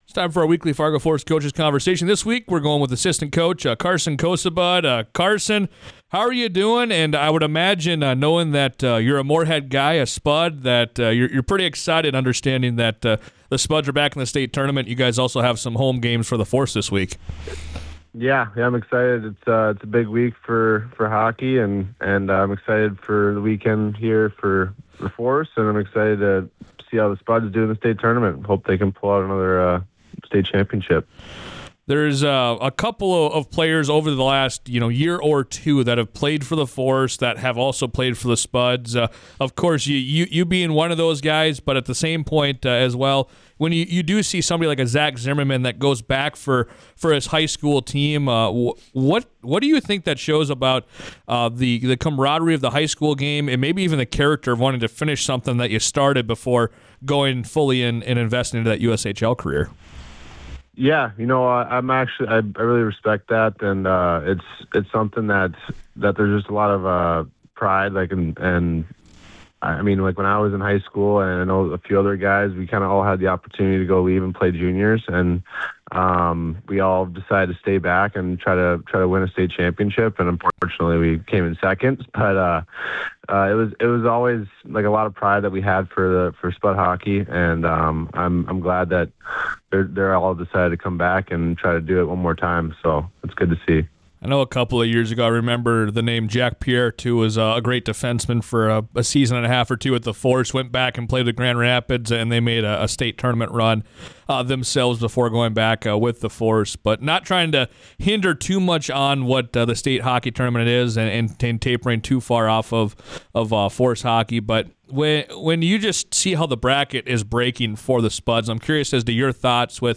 for their weekly Force coaches conversation. They talked the Minnesota state hockey tournament that features the Moorhead Spuds, discussed the home stretch of the regular season, and more.